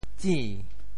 Details of the phonetic ‘zin2’ in region TeoThew
IPA [tsĩ]